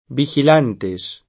biKil#nte]; pl. vigilantes [